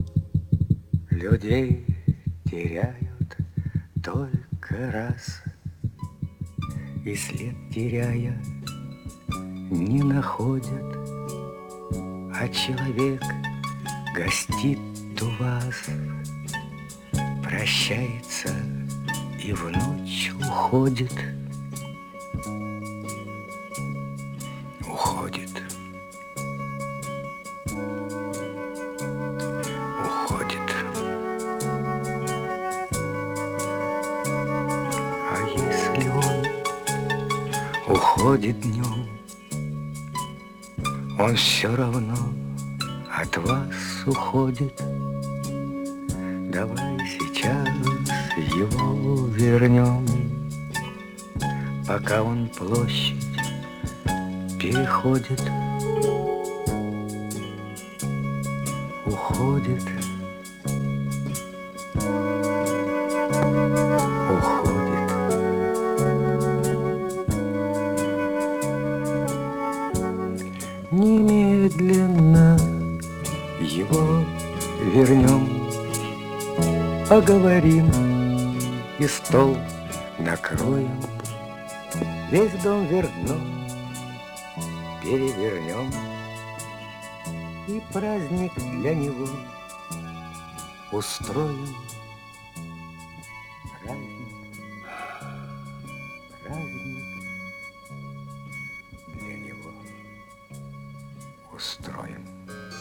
Звуковая допожка взята из оригинального кинофильма.